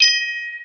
ui_chime.wav